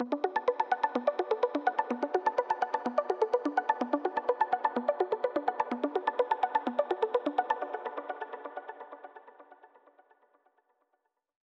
VDE 126BPM Klondike Arp Root B.wav